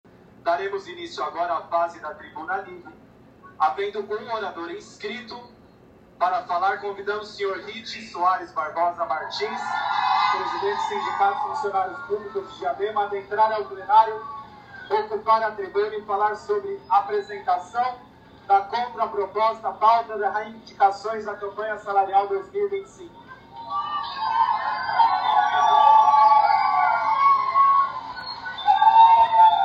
Já se iniciava a tribuna com a fala do presidente da câmara sendo abafada pelos gritos do povo ao notarem o anúncio do representante dos servidores públicos: “Daremos início agora à fase da Tribuna Livre, havendo um orador inscrito.